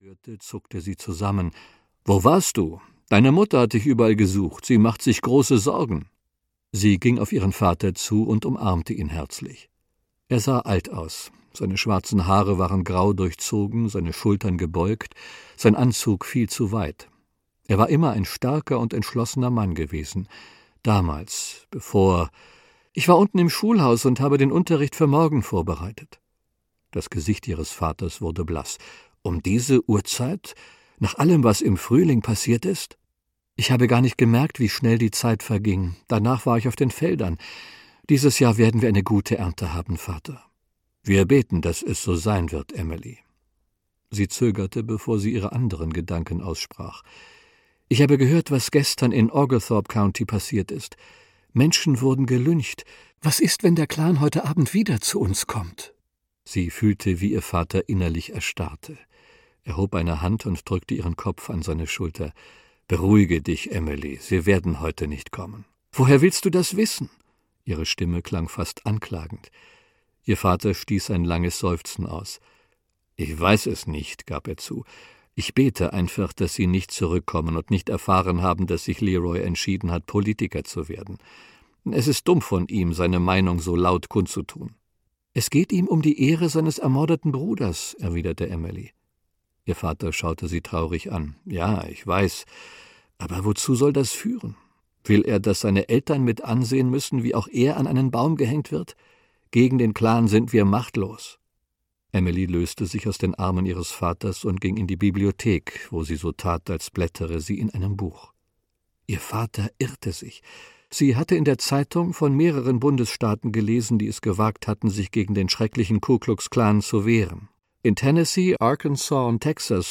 Im Schatten der Magnolien - Elizabeth Musser - Hörbuch